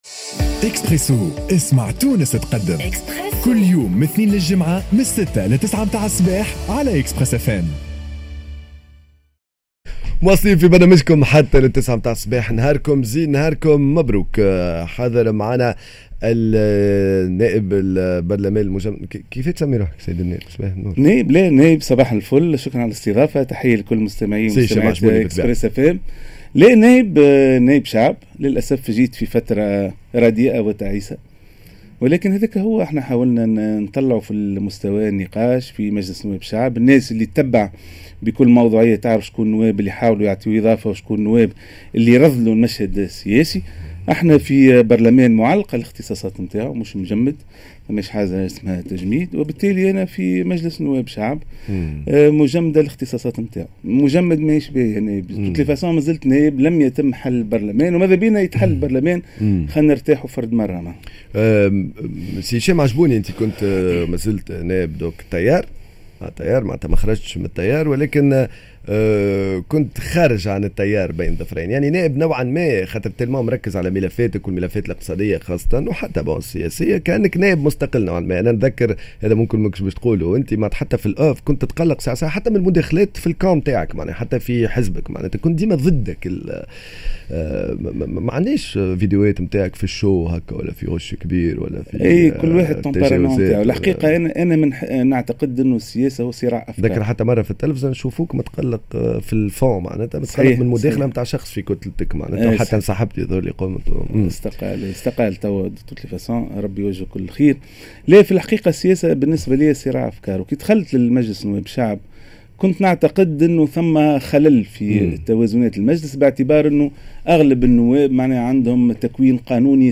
L'interview: هشام العجبوني القيادي في حزب التيار الديمقراطي